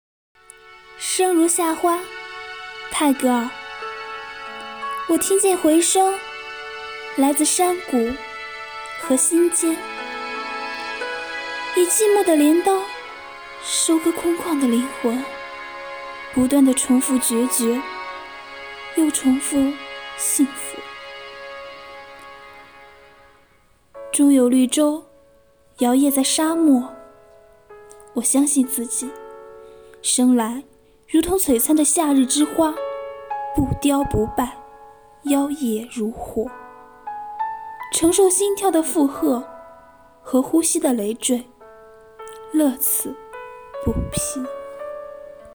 “阅读的力量 -- 读给你听”主题朗诵